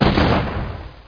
impact2.mp3